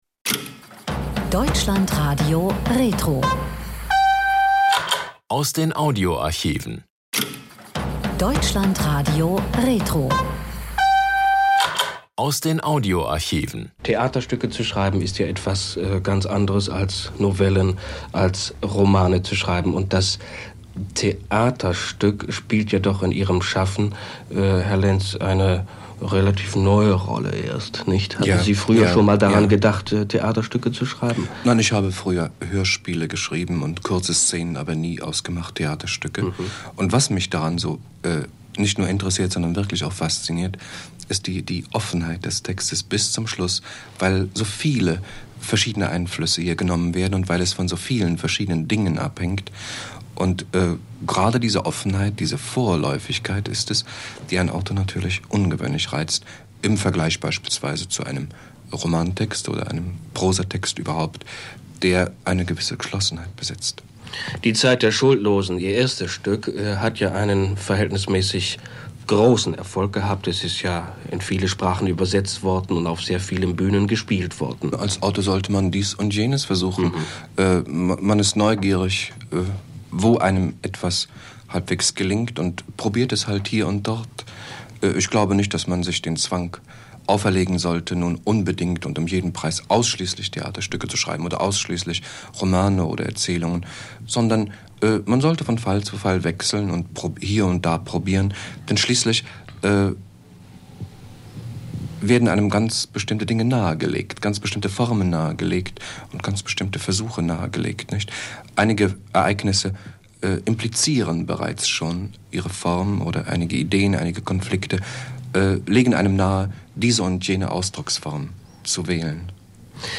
Siegfried Lenz über "Das Gesicht": DLF-Gespräch zur Uraufführung